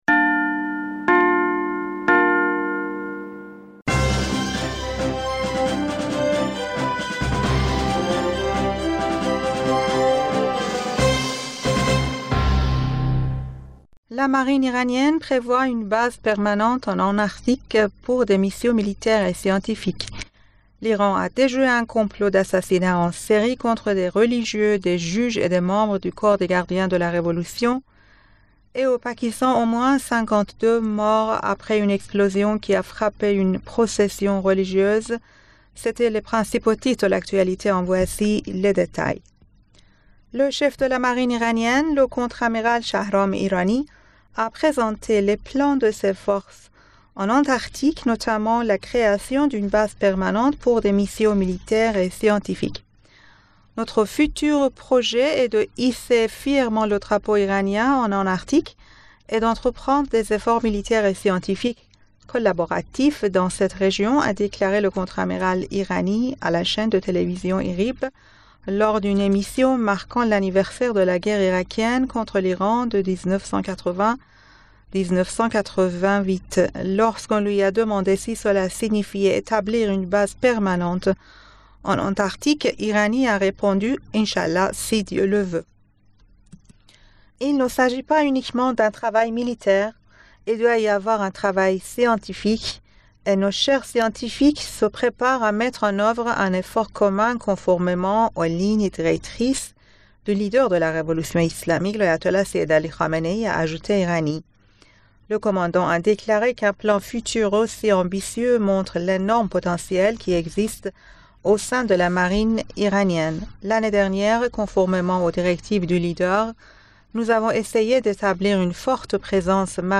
Bulletin d'information du 29 Septembre 2023